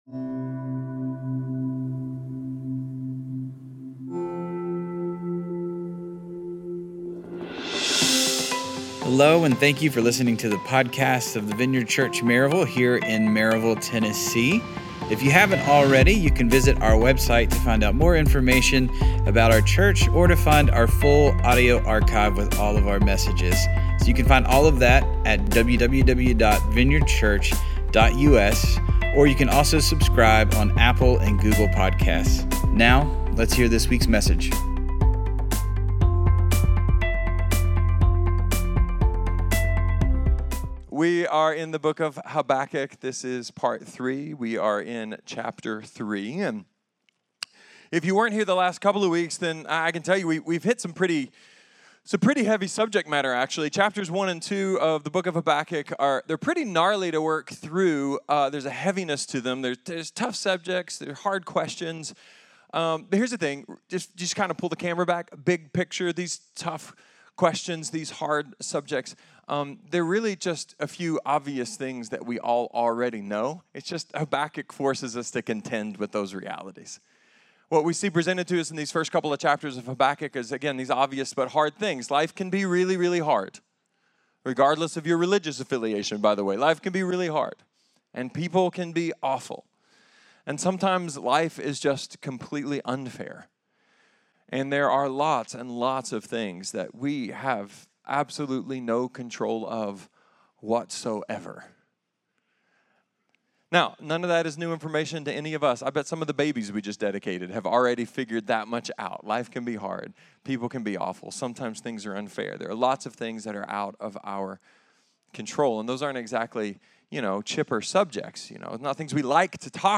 A message from the series "Habakkuk."